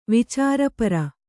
♪ vicāra para